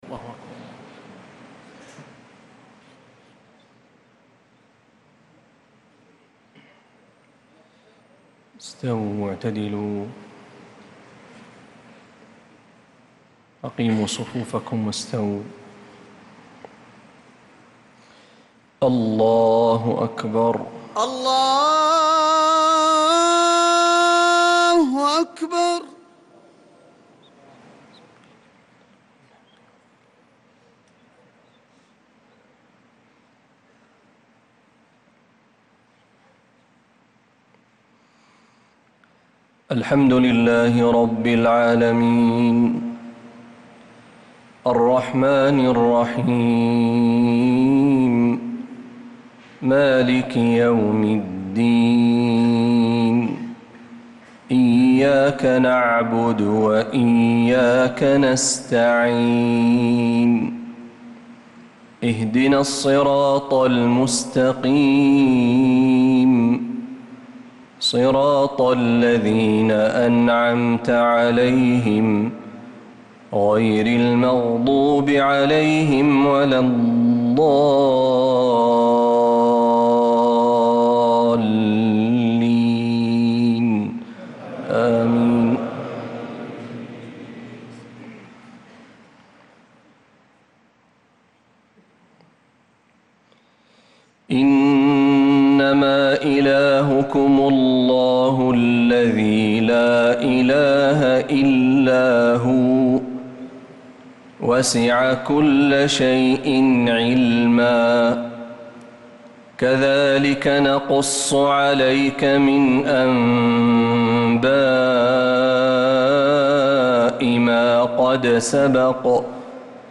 صلاة العشاء للقارئ محمد برهجي 7 ربيع الآخر 1446 هـ
تِلَاوَات الْحَرَمَيْن .